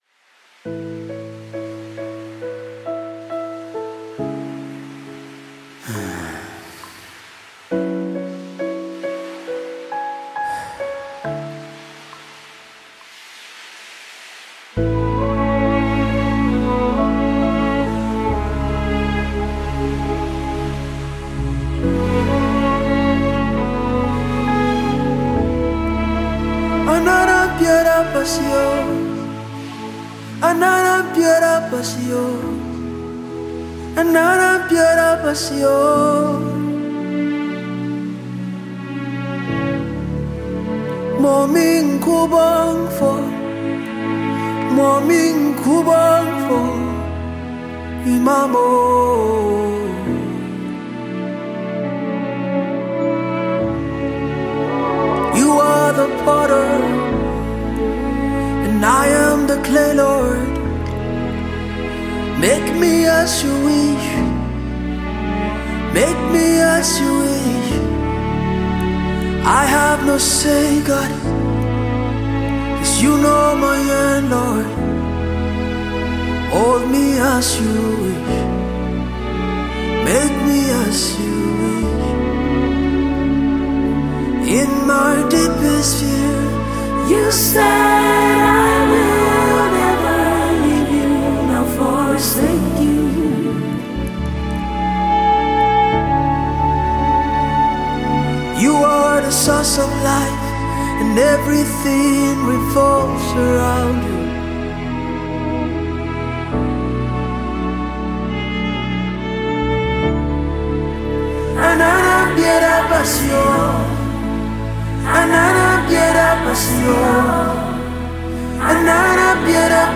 GospelMusic
Nigeria Gospel Singer and songwriter